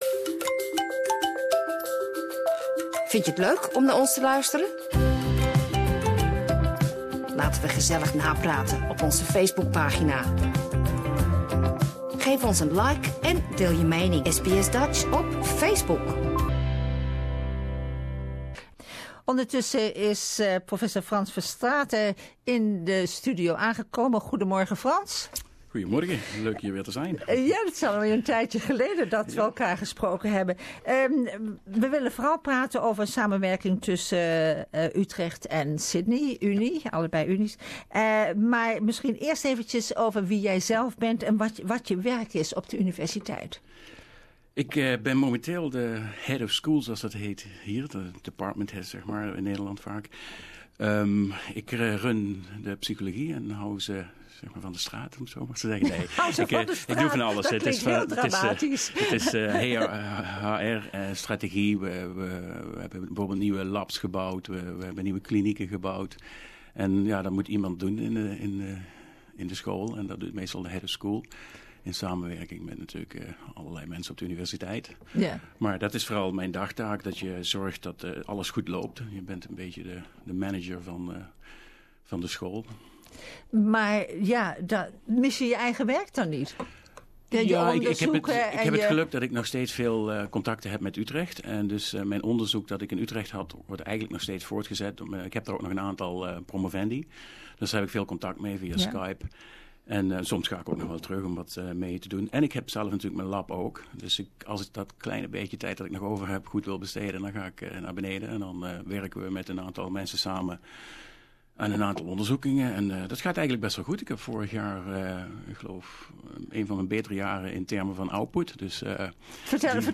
He came to the studio to talk about the value of collaborating with Utrecht university and "blue sky research" where the commercial value is not always immediately apparent.